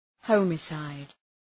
Προφορά
{‘hɒmı,saıd}